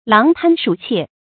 狼贪鼠窃 láng tān shǔ qiè 成语解释 如狼那样贪狠；似鼠那样惯窃。